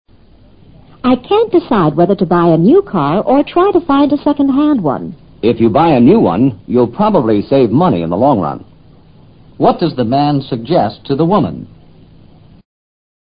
托福听力小对话【22】